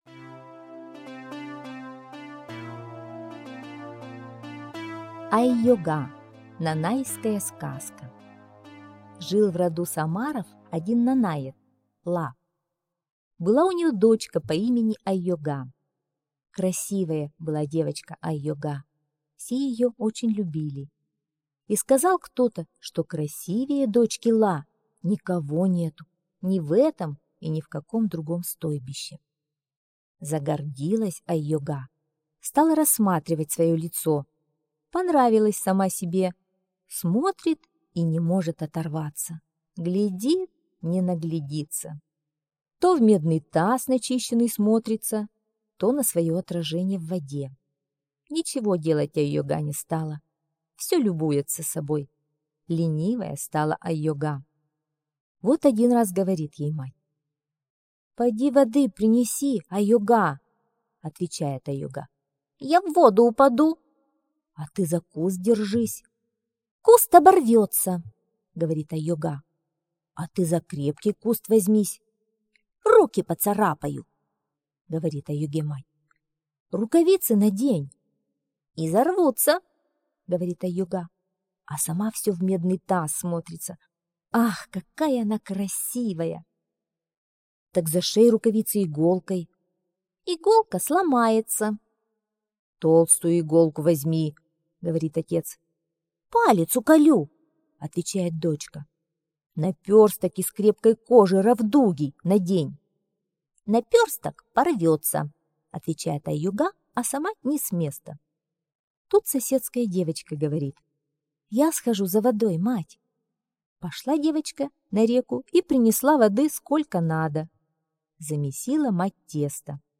Айога - нанайская аудиосказка - слушать онлайн